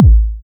KICK17.wav